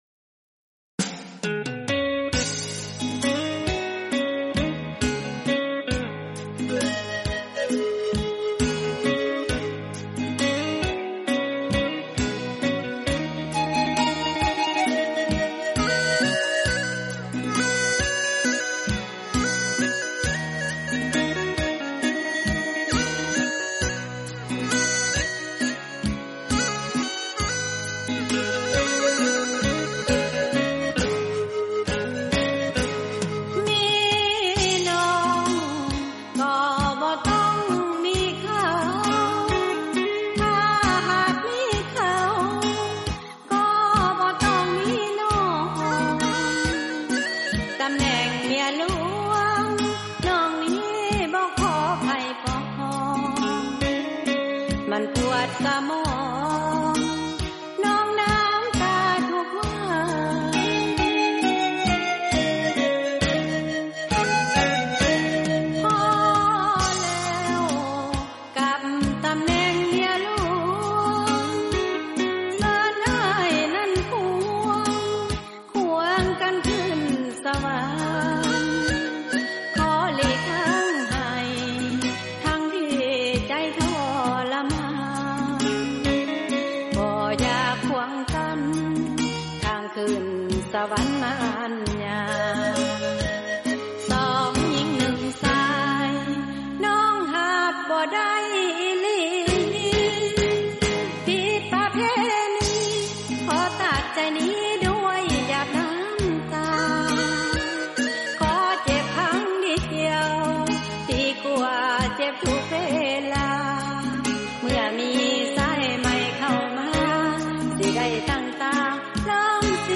ດົນຕີ